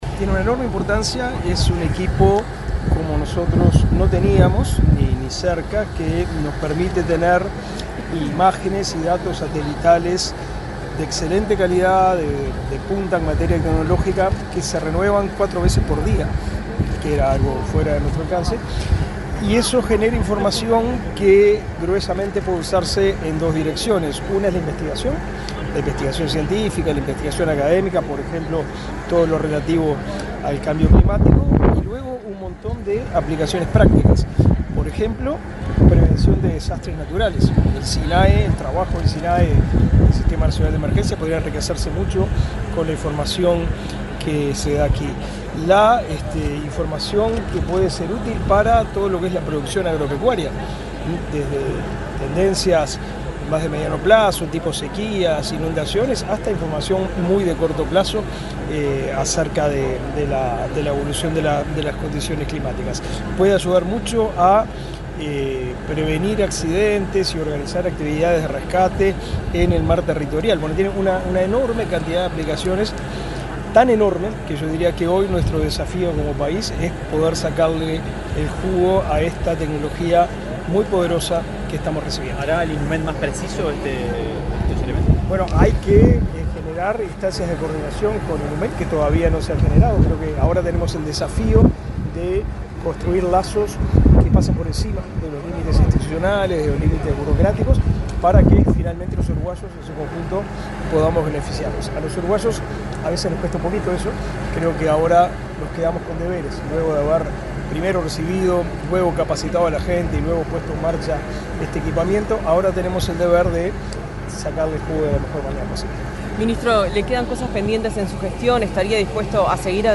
Declaraciones del ministro de Educación y Cultura, Pablo da Silveira
Declaraciones del ministro de Educación y Cultura, Pablo da Silveira 22/10/2024 Compartir Facebook X Copiar enlace WhatsApp LinkedIn El ministro de Educación y Cultura, Pablo da Silveira, dialogó con la prensa, luego de participar en el acto de presentación de un sistema transportable para la recepción, el procesamiento y la aplicación de datos meteorológicos de integración multisatelital, donado por la República Popular China.